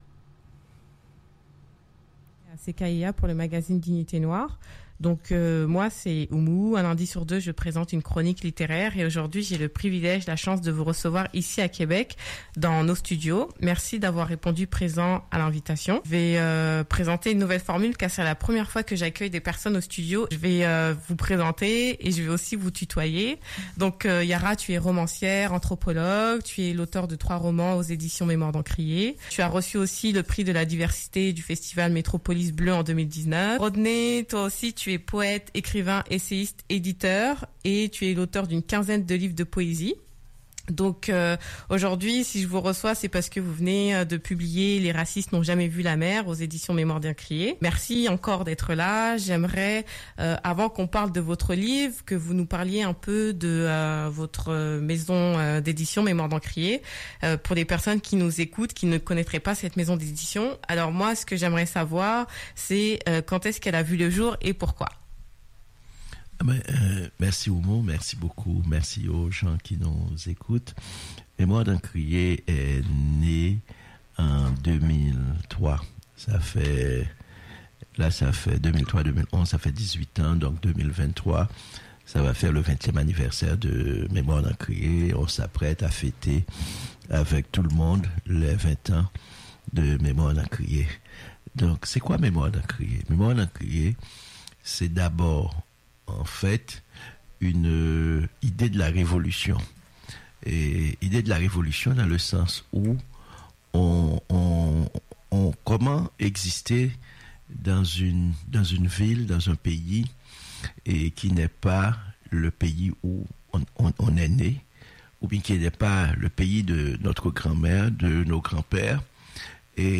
Vendredi 5 novembre, c’était la première fois que j’accueillais au studio de CKIA FM, des invités pour un entretien live à la radio.